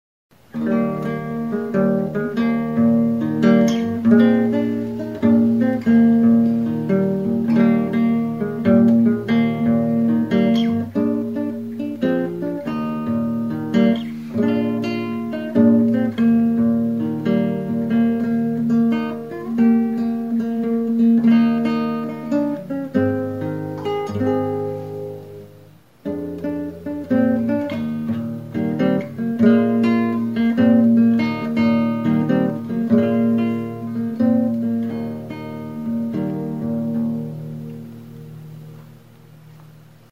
Stringed -> Plucked
Recorded with this music instrument.
GITARRA; GUITARRA
Sei sokazko gitarra arrunta da.